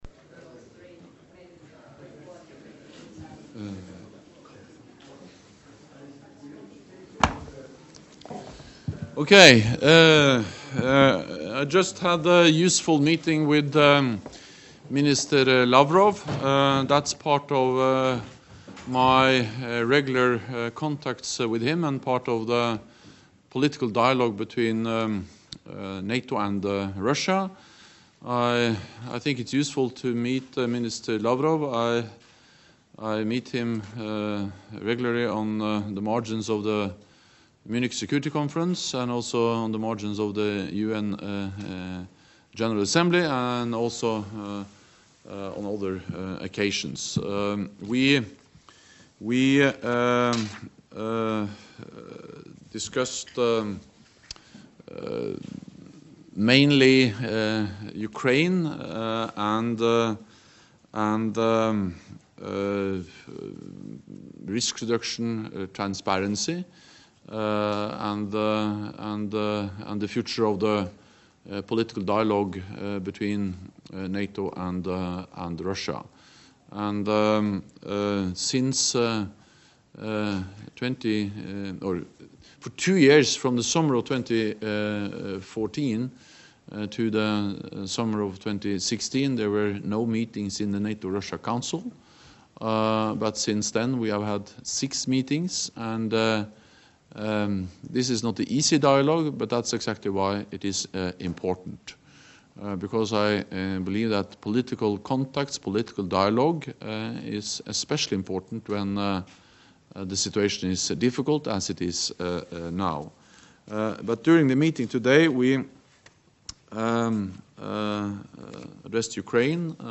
NATO Secretary General Jens Stoltenberg round table with media at the Munich Security Conference